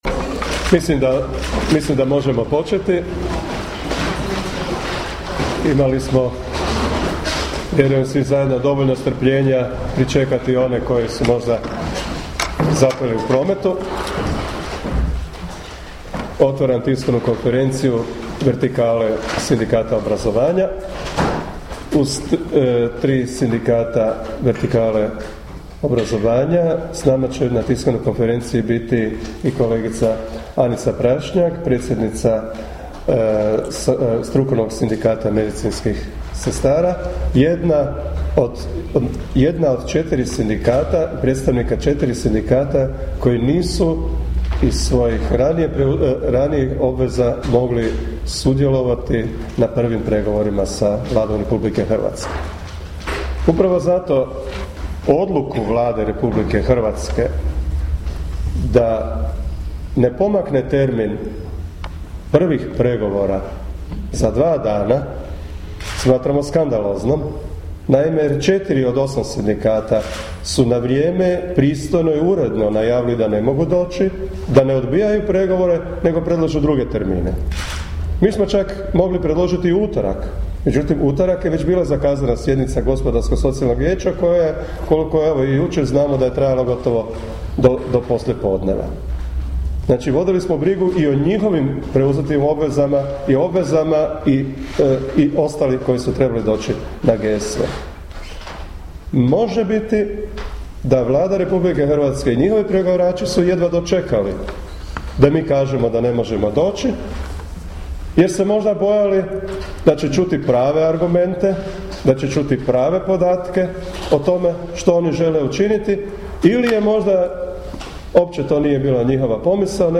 Tiskovna konferencija Matice hrvatskih sindikata
Predsjednici sindikata udruženih u Maticu hrvatskih sindikata održali su 6. lipnja 2012. godine tiskovnu konferenciju o pregovorima oko kolektivnih ugovora i odricanjima koje traži i najavljuje Vlada RH.